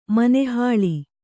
♪ mane hāḷi